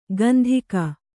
♪ gandhika